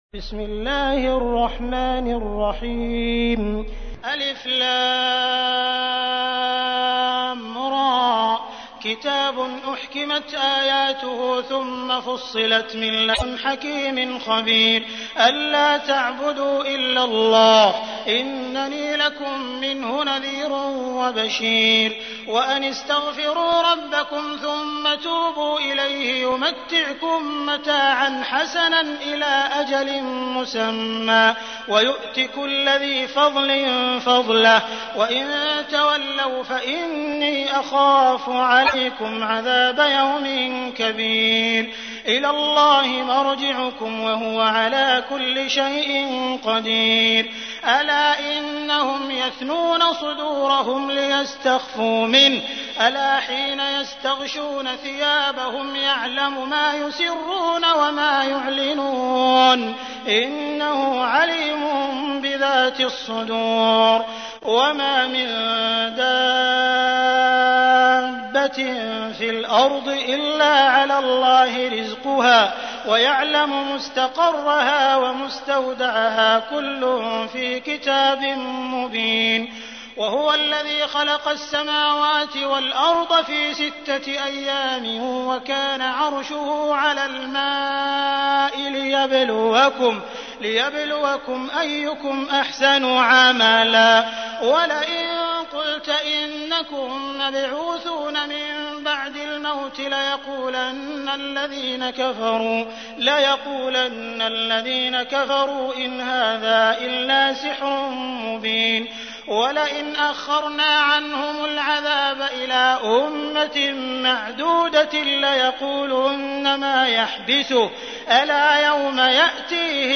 تحميل : 11. سورة هود / القارئ عبد الرحمن السديس / القرآن الكريم / موقع يا حسين